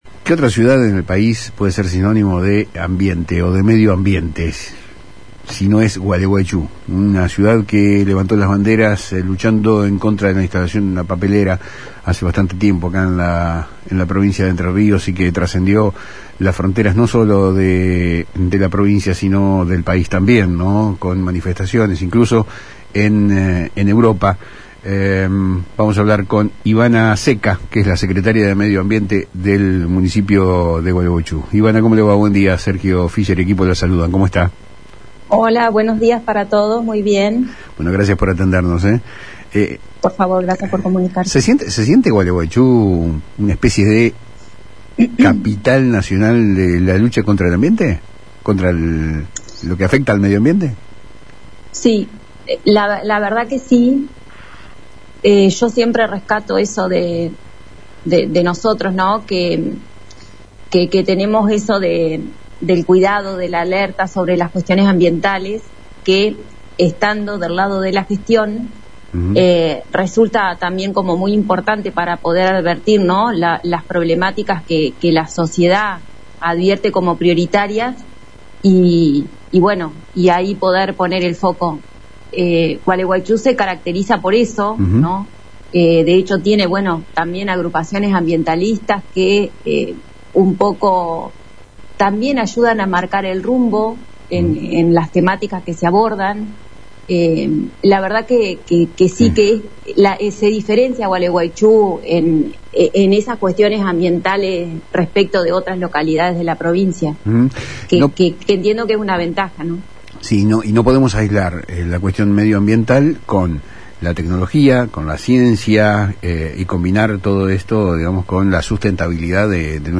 En diálogo con Palabras Cruzadas por FM Litoral, Ivana Zecca, Secretaria de Medio Ambiente del municipio de Gualeguaychú, reconoció el rol de su ciudad: «Sí, la verdad que sí. Yo siempre rescato eso de nosotros, que tenemos eso del cuidado, de la alerta sobre las cuestiones ambientales».